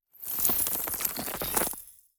Free Frost Mage - SFX
Crystallize_02.wav